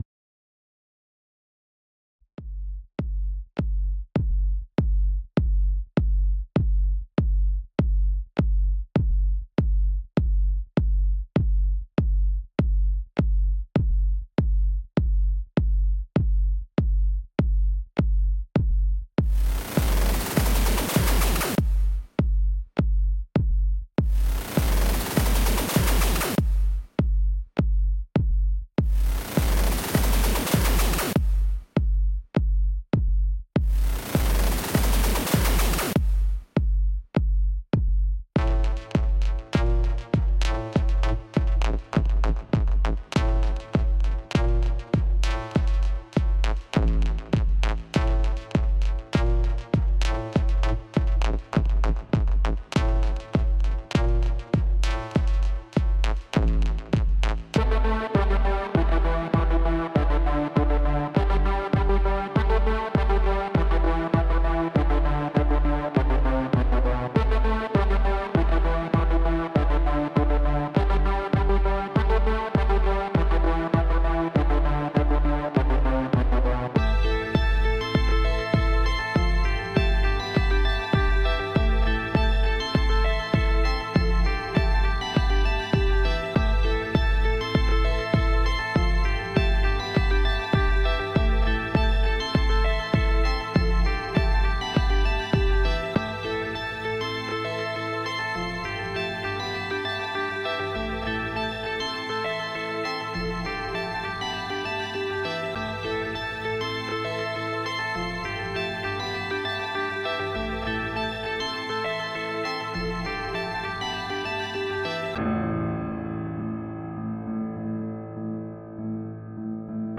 • Жанр: Электронная